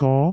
speech
syllable
pronunciation
go2.wav